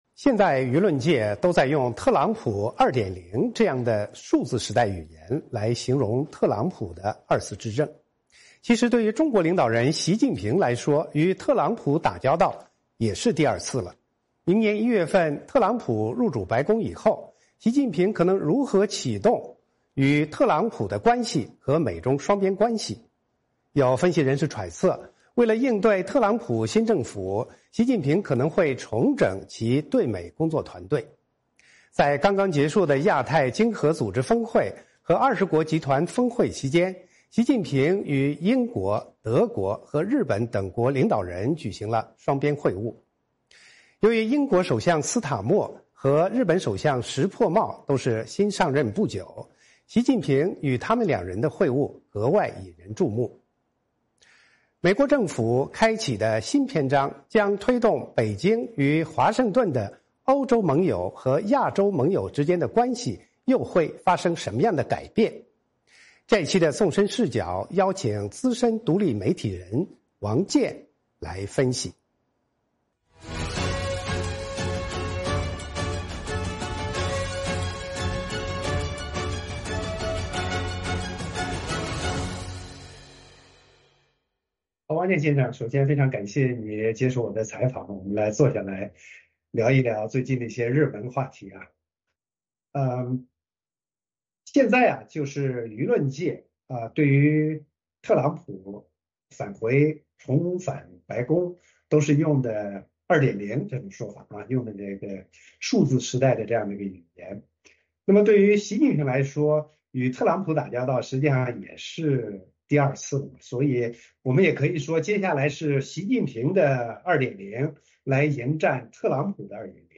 《纵深视角》节目进行一系列人物专访，受访者所发表的评论不代表美国之音的立场。